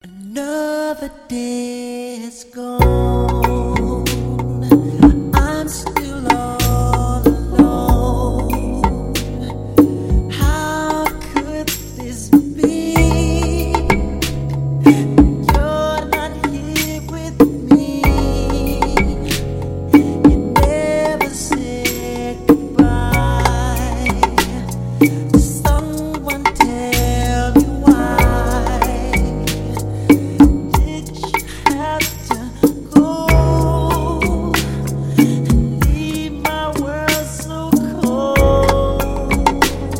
поп , спокойные
медленные , соул , романтические